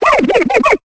Cri de Brindibou dans Pokémon Épée et Bouclier.